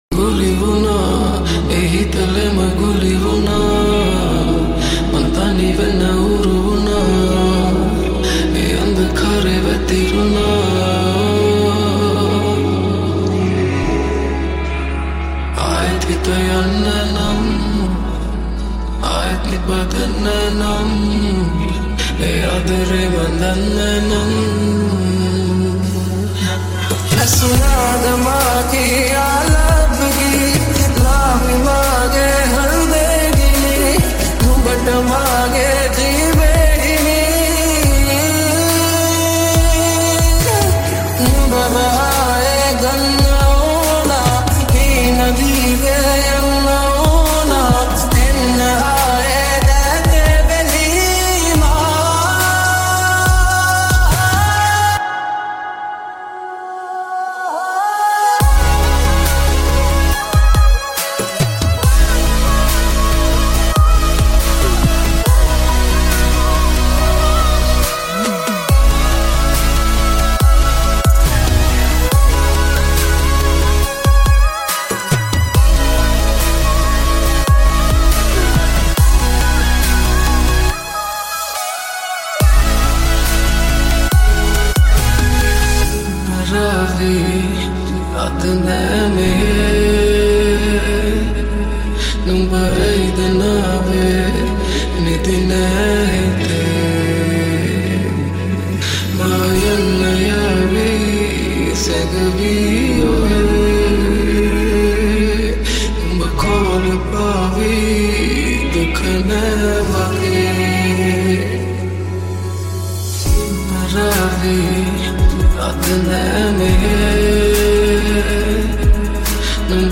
Genre - Trap